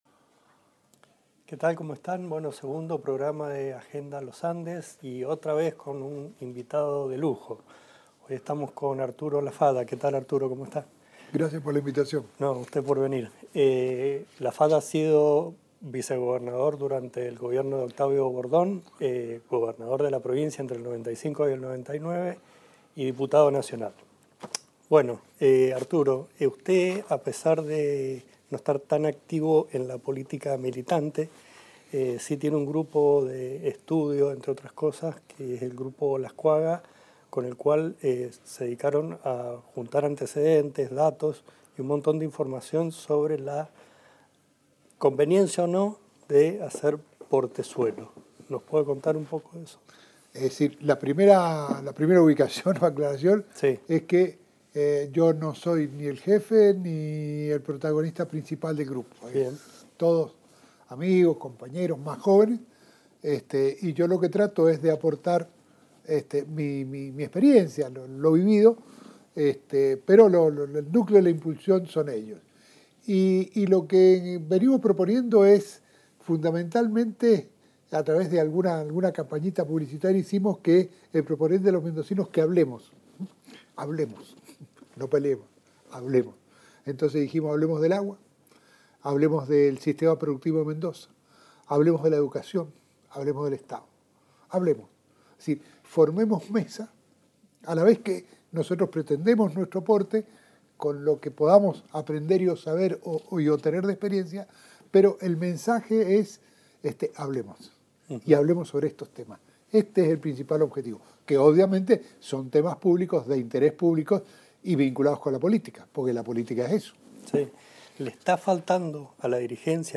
Agenda Los Andes es un programa de Diario Los Andes y la UNCuyo sobre temas de interés general. El ciclo reúne a los principales referentes locales de diversos ámbitos en entrevistas íntimas.